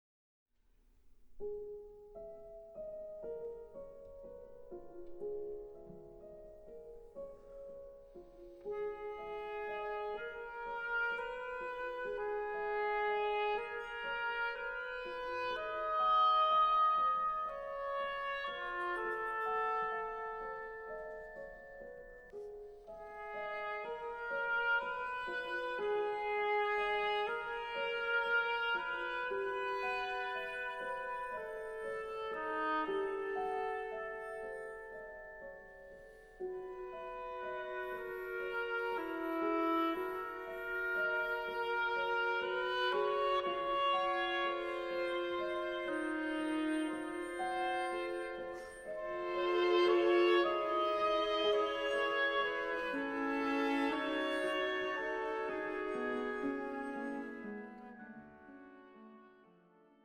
Reel (2:44)
Oboe